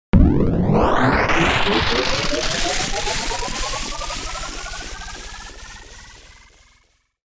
描述：Intended for game creation: sounds of bigger and smaller spaceships and other noises very common in airless space. How I made them: Rubbing different things on different surfaces in front of 2 x AKG C1000S, then processing them with the free Kjearhus plugins and some guitaramp simulators.
标签： Spaceship GameCreation Warpdrive Warp Space Outer Phaser
声道立体声